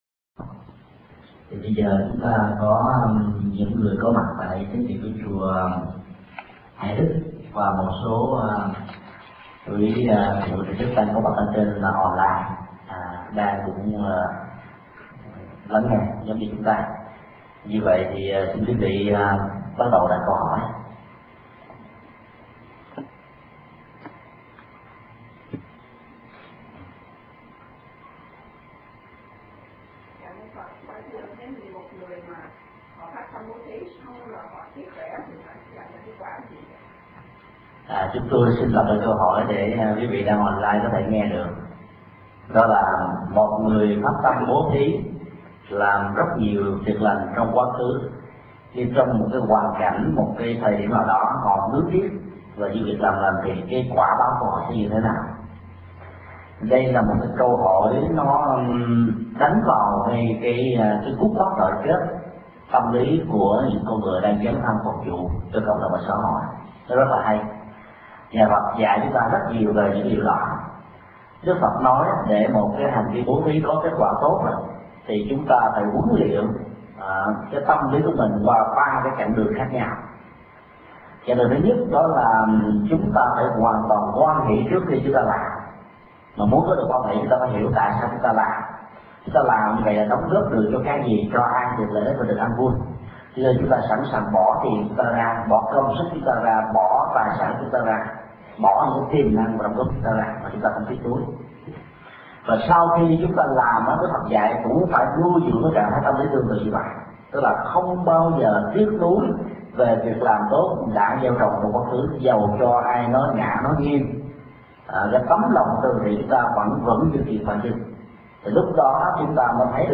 Mp3 Thuyết Pháp Tinh thần quà tặng
Giảng tại Chùa Hải Đức, Jacksonville